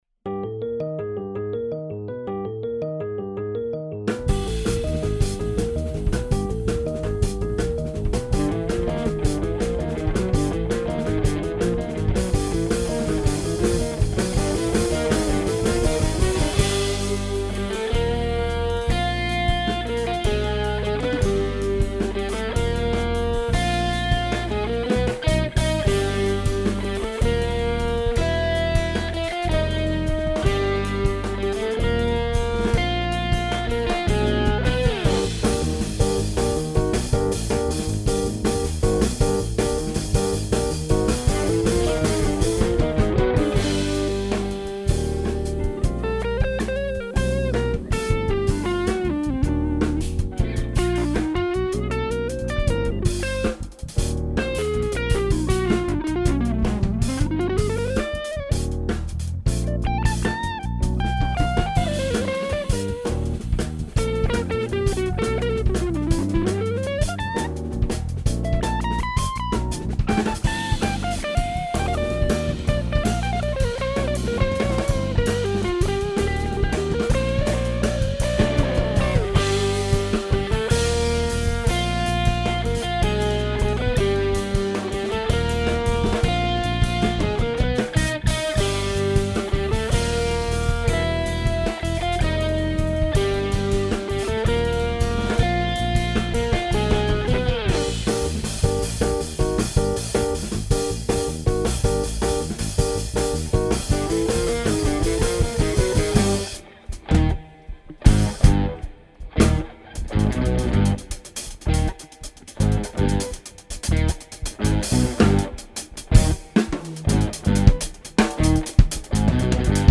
Live Room
impressive drums playing